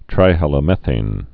(trīhăl-ə-mĕthān)